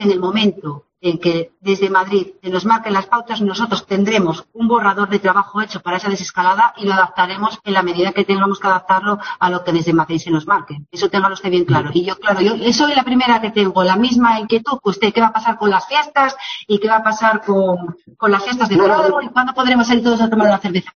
Rita Camblor, que esta mañana ha comparecido por videoconferencia ante el grupo de trabajo creado en la Junta General del Principado para abordar todo lo relacionado con la pandemia, también ha asegurado que el Principado ya comenzó a tomar medidas en febrero para hacer frente al coronavirus.